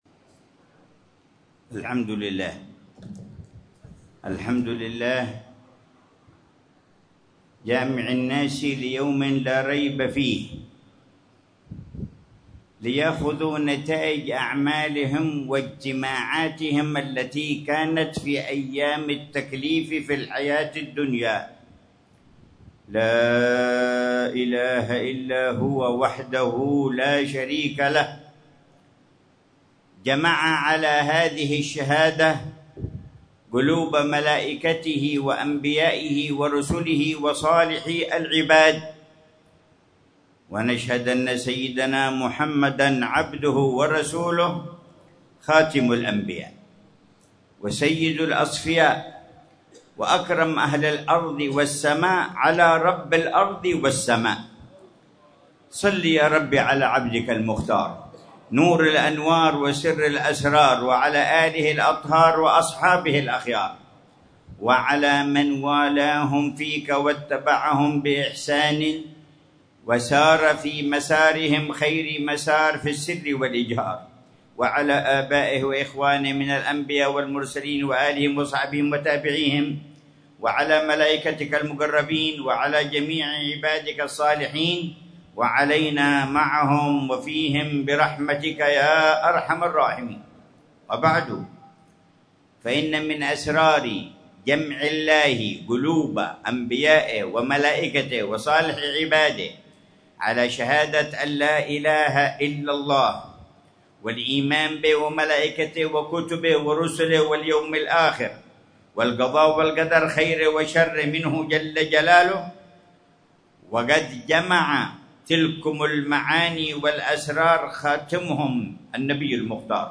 محاضرة العلامة الحبيب عمر بن محمد بن حفيظ في الاحتفال بالمولد النبوي في منطقة زهر الجنان، في وادي بن علي،حضرموت، ليلة الإثنين 30 ربيع الأول 1447هـ بعنوان: